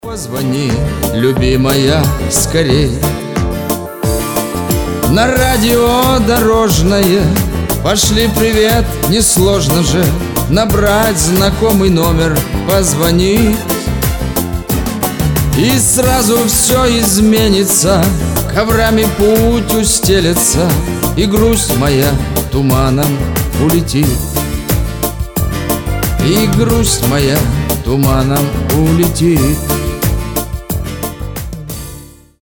• Качество: 320, Stereo
душевные
дорожные